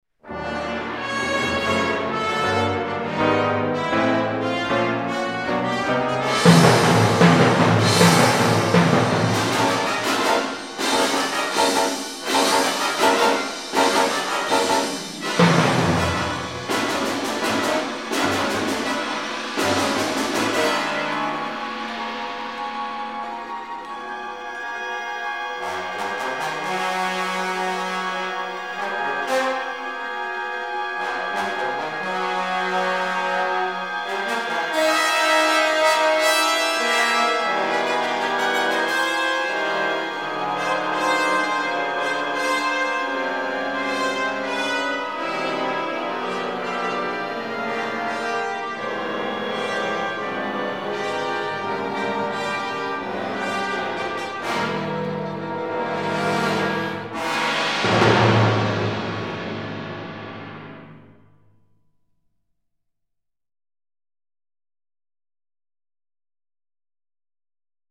startling and memorable